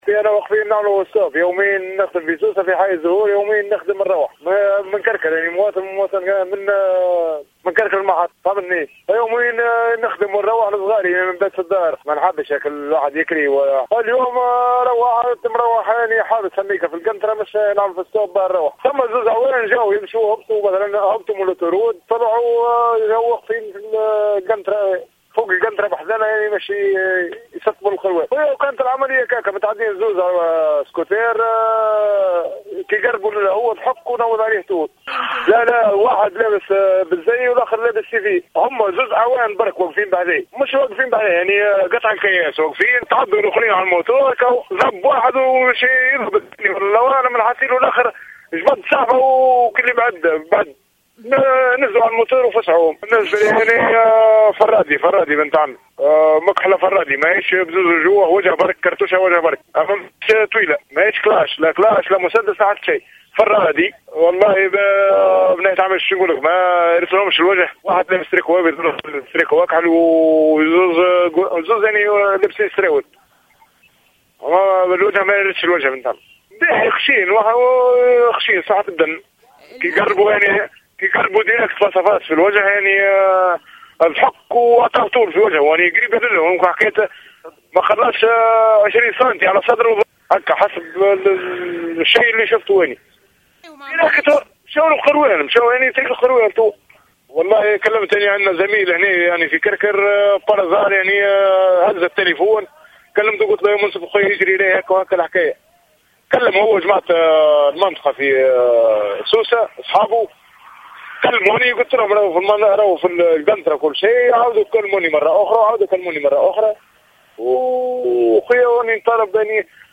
شاهد عيان يروي تفاصيل إطلاق النار على أعوان أمن في حي الزهور